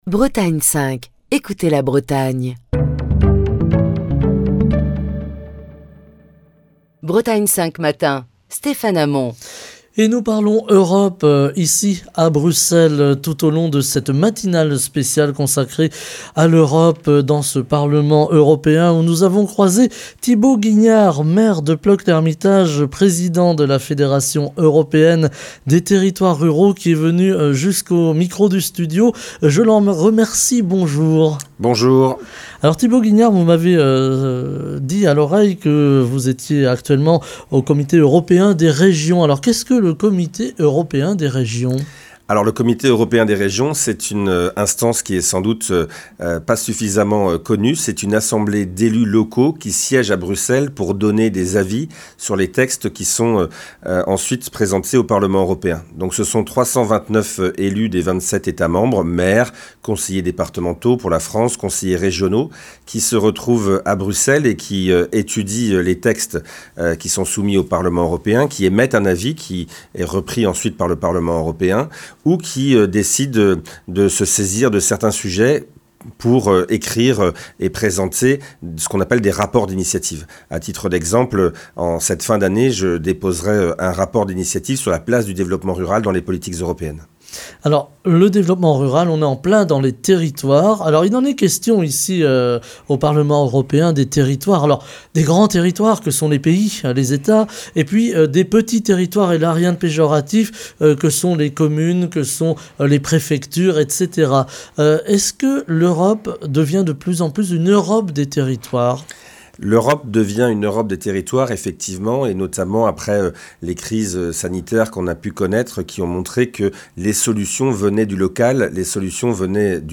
Matinale spéciale Europe depuis le Parlement européen à Bruxelles. Ce matin nous parlons du rôle du Comité européen des régions avec notre invité, Thibaut Guignard, président de la Fédération européenne des territoires ruraux (Elard), maire de Plœuc-L’Hermitage dans les Côtes-d'Armor.